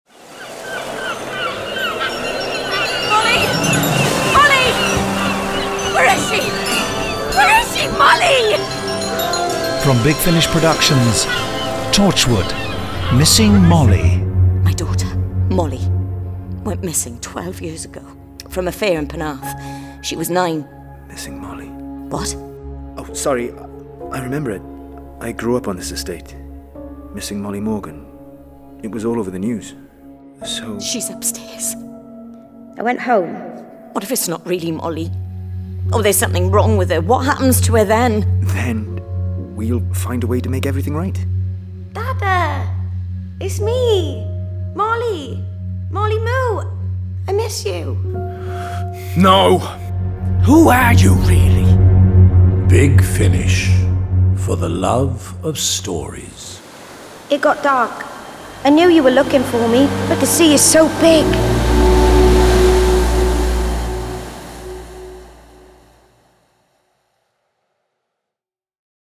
Torchwood: Missing Molly Released April 2024 Written by Gareth David-Lloyd Starring Gareth David-Lloyd This release contains adult material and may not be suitable for younger listeners. From US $12.12 CD + Download US $14.82 Buy Download US $12.12 Buy Save money with a bundle Login to wishlist 12 Listeners recommend this Share Tweet Listen to the trailer Download the trailer